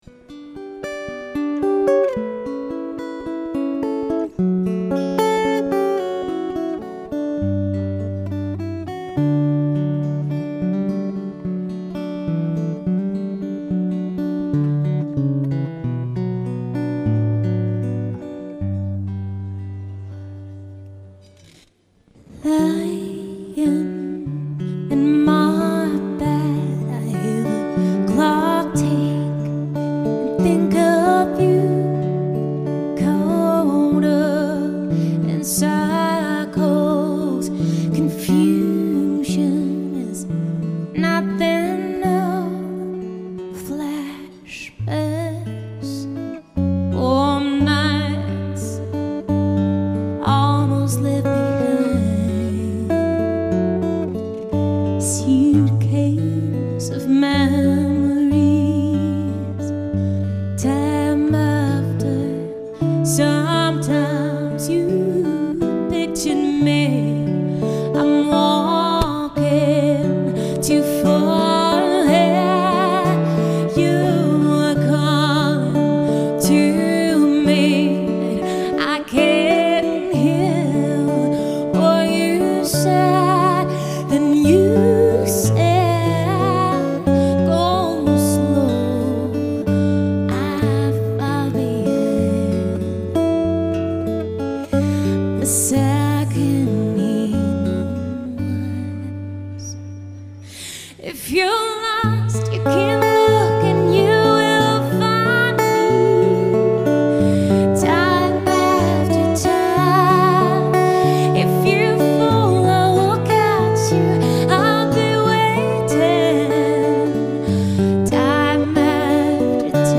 Programme d'orchestre de variété avec chanteuse et chanteur
Guit. Electriques, Sèche, Nylon Piano & Clavier